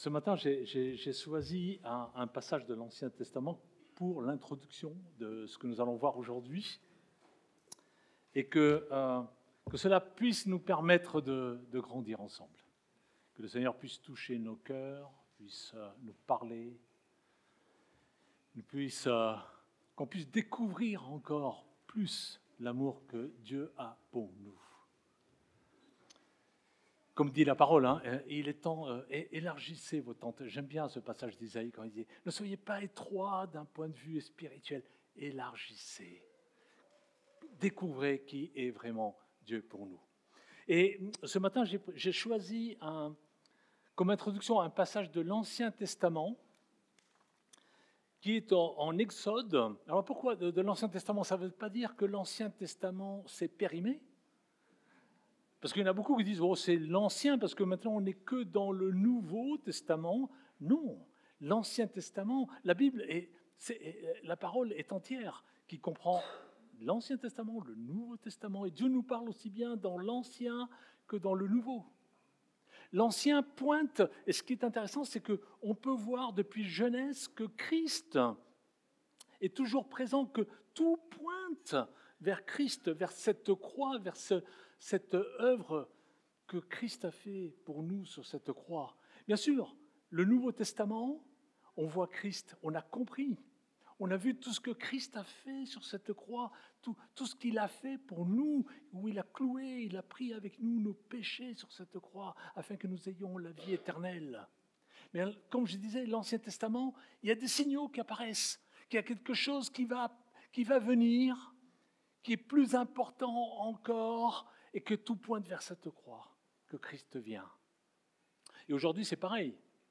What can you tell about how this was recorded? Culte du dimanche 19 Avril 26